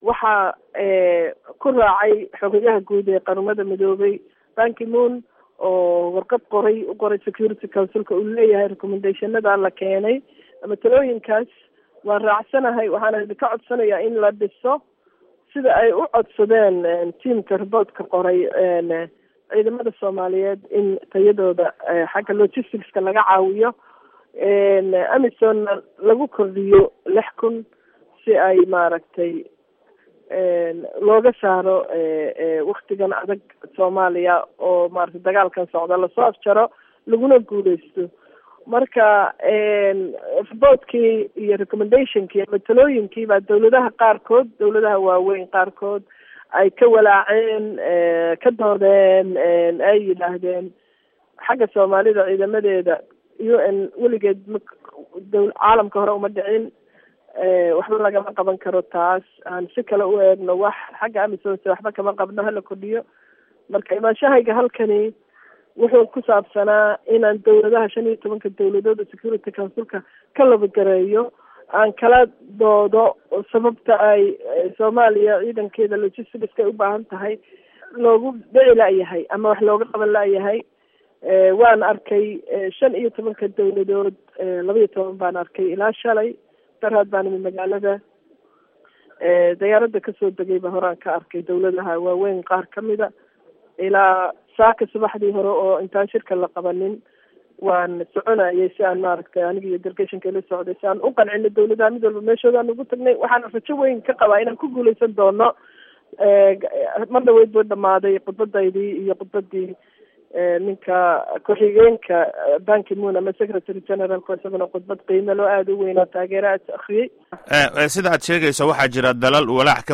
Waraysiga Ra'iisal Wasaare ku Xigeenka ahna W/Arr/Dibadda